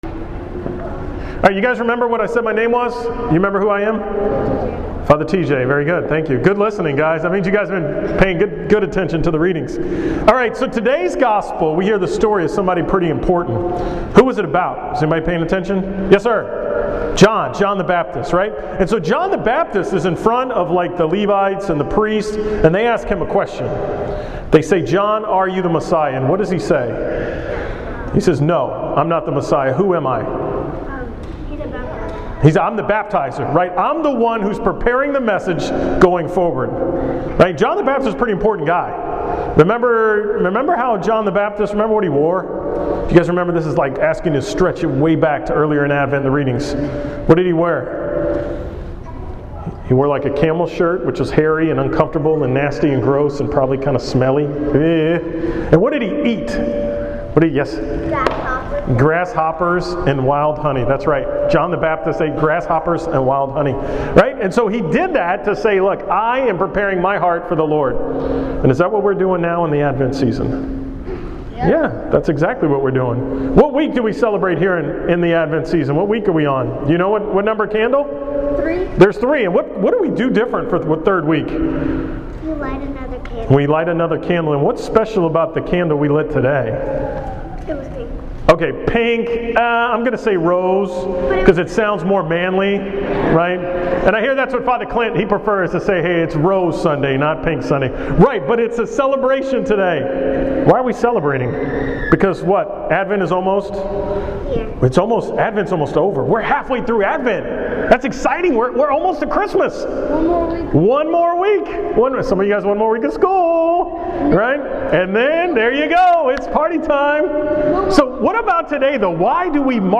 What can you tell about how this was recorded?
From the 10 am Mass at St. Rose of Lima on December 14, 2014